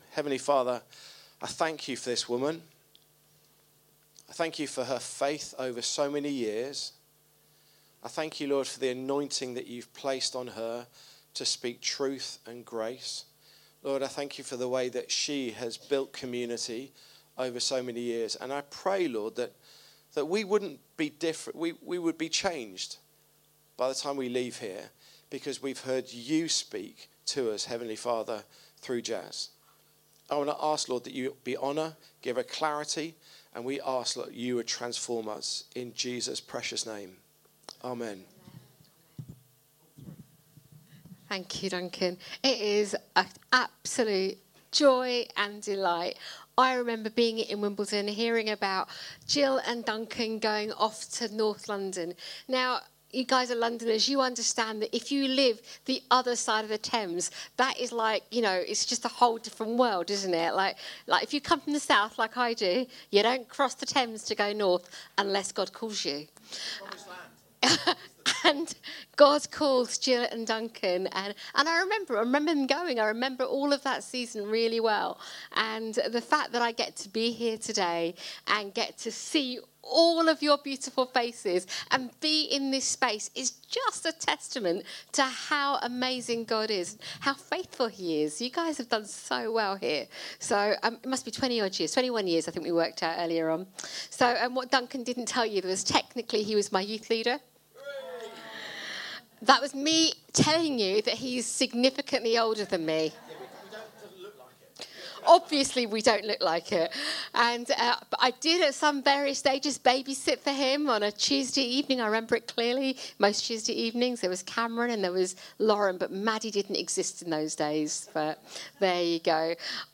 Download Sent To Build Friendship | Sermons at Trinity Church